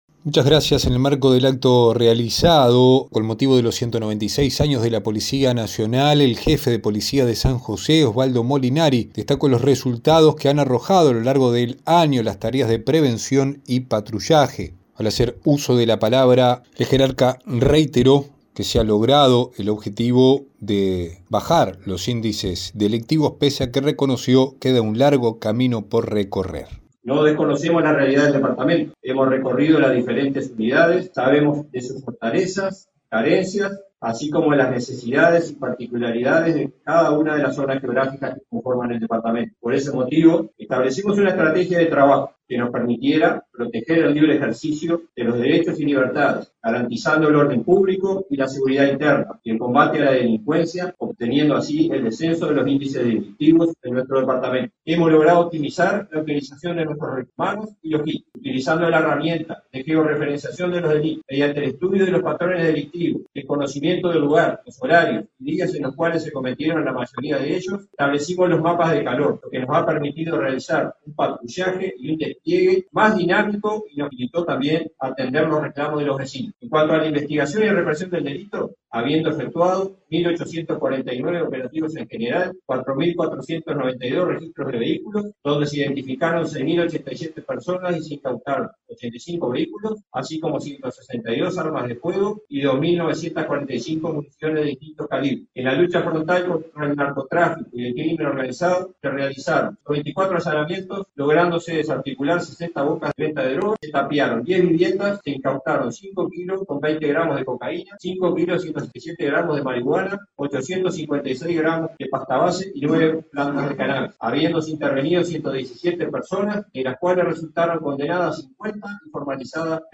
En el marco del acto por los 196 años de la Policía Nacional, realizado en las últimas horas, el jefe de Policía de San José, Osvaldo Molinari, presentó una evaluación del trabajo desarrollado durante el año, con énfasis en las tareas de prevención, patrullaje y combate al delito.